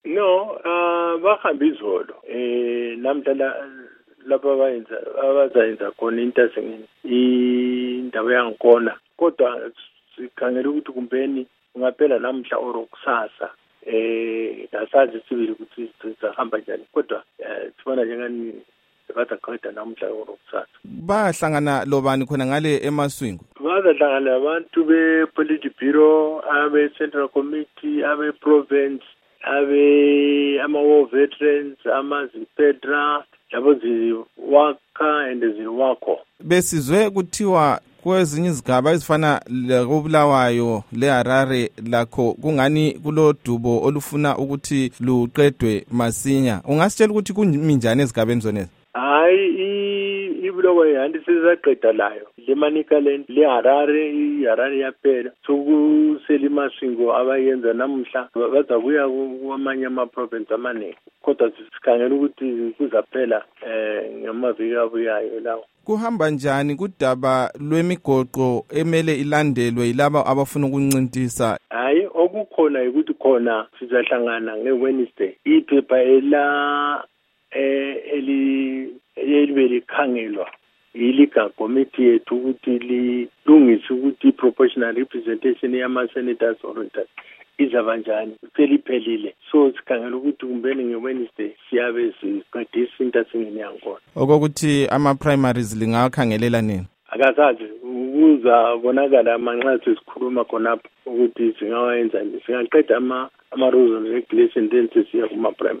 Ingxoxo loMnu Rugare Gumbo